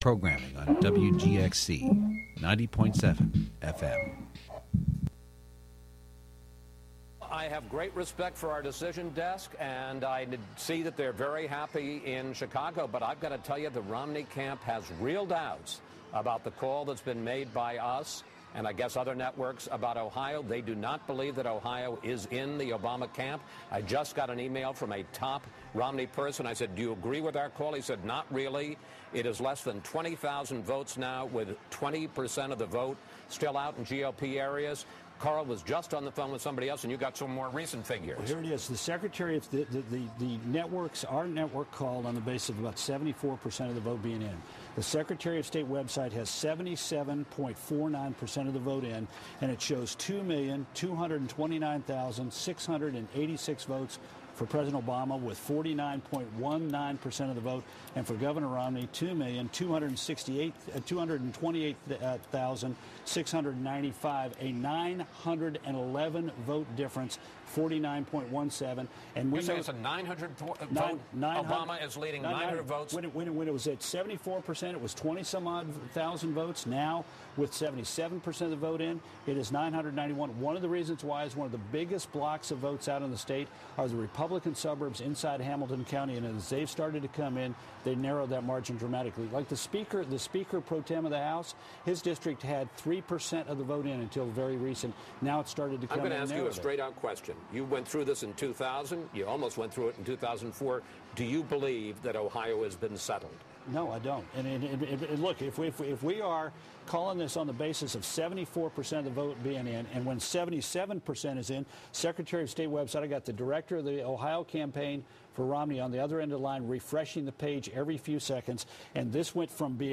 Radio Theatre: Election Media Theatre (Audio)